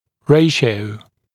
[‘reɪʃɪəu][‘рэйшиоу]отношение, пропорция, коэффициент, соотношение